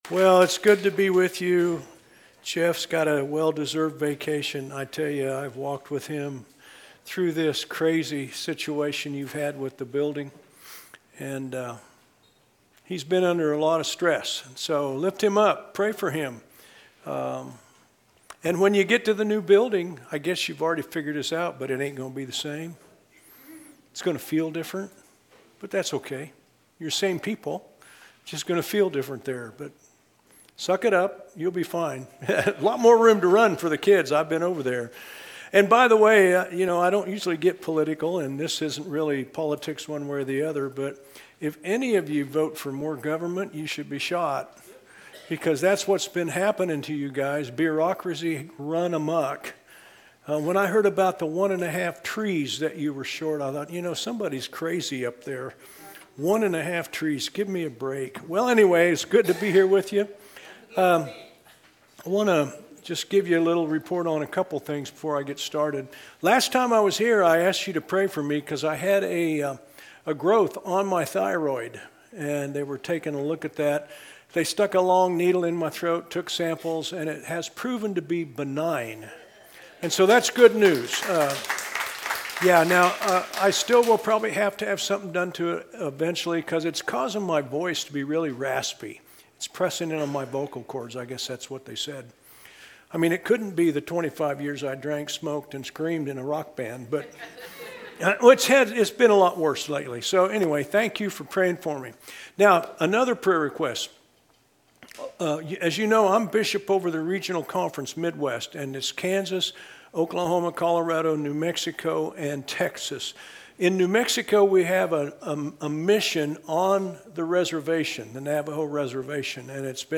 A sermon from the series "Guest." Have you ever wondered how ancient poetry connects to Jesus' story?